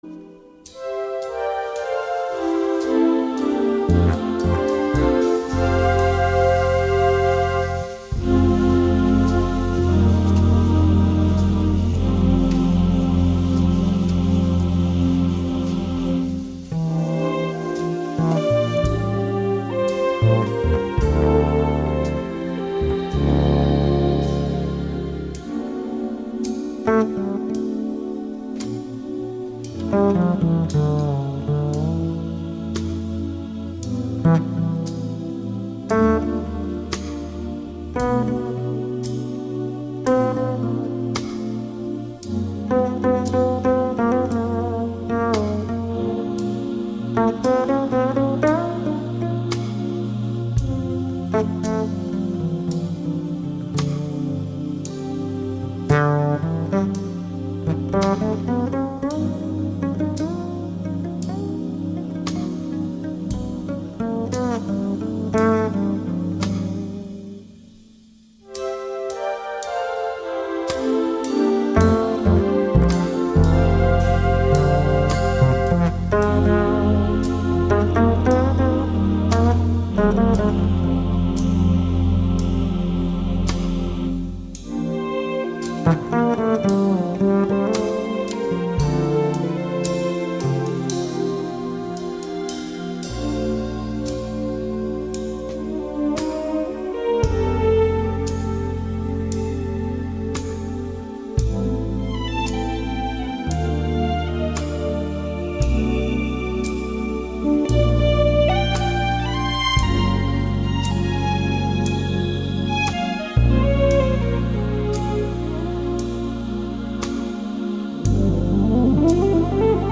Acoustic Bass